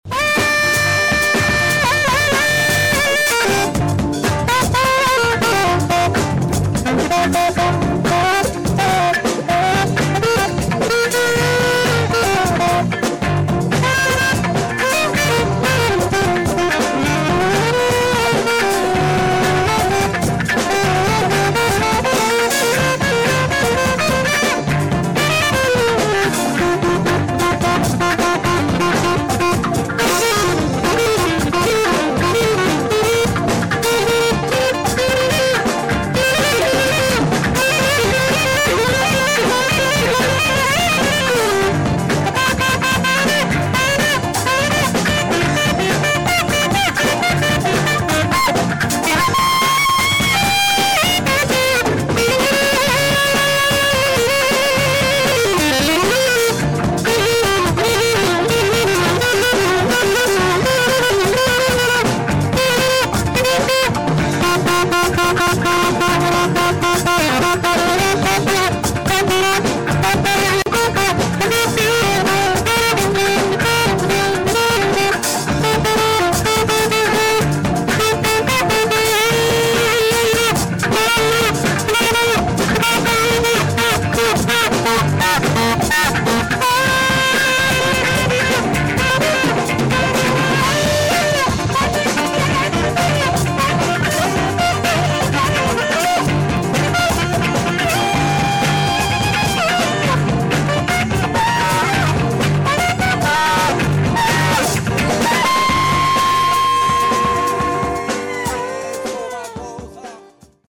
Live à Grasse Afro Funk
saxophone
Formation restreinte.